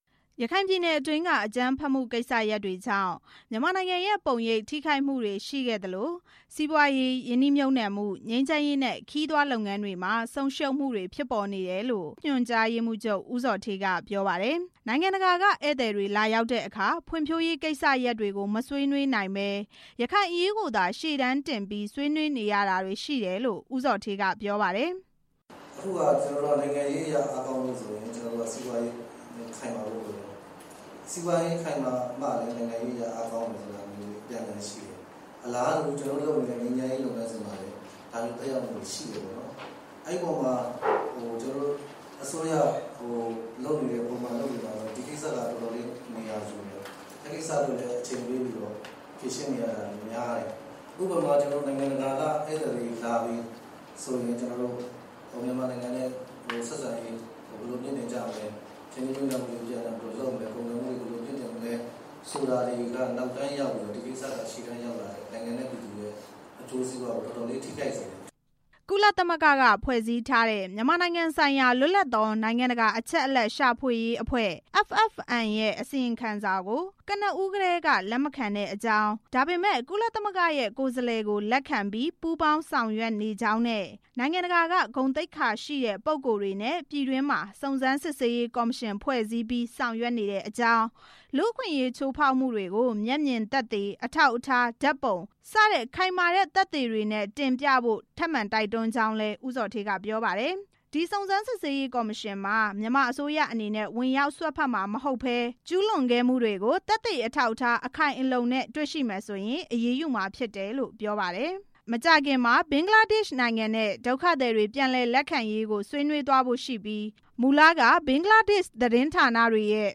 အတိုင်ပင်ခံရုံး သတင်းစာရှင်းလင်းပွဲ